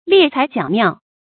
埒材角妙 liè cái jiǎo miào
埒材角妙发音